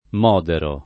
moderare v.; modero [ m 0 dero ]